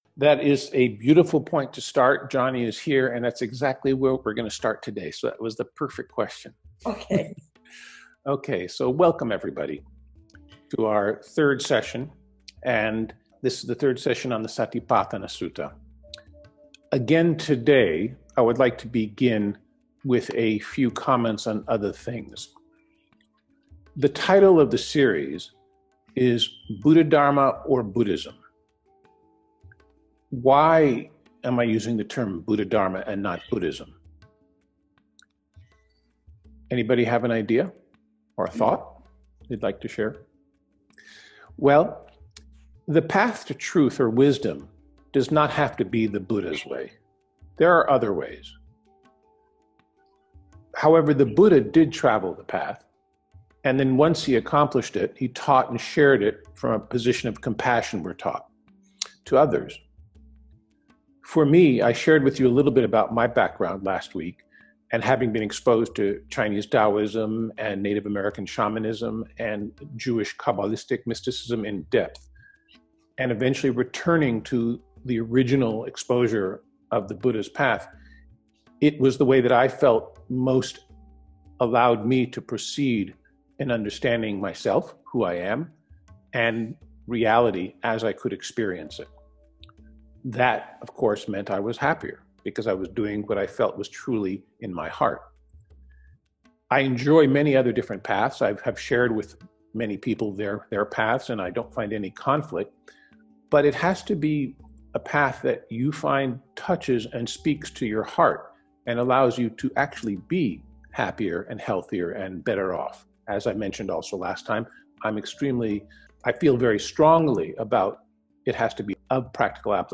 Path to Wisdom :: Conversation
Continuing in our exploration of Buddhdaharma we will be continuing to discuss the Satipatthana Sutta, an essential instruction in Vipassanā. This Audio is a 20 minute excerpt from the Discourse to present a flavour of what occurs during a discourse.